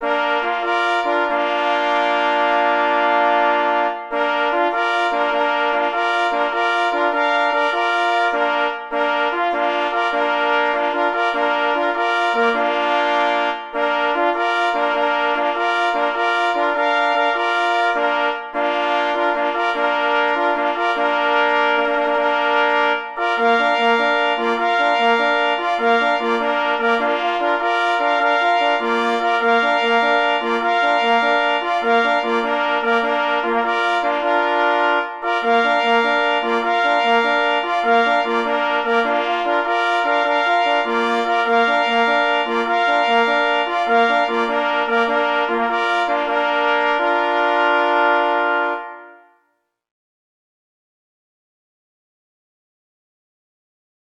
na dwa plesy i parfors
Gatunek utworu: miniatura Strój: B
na 2 plesy i parfors